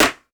Foley Sports / Football - Rugby / Helmet Impact Normal.wav
Helmet Impact Normal.wav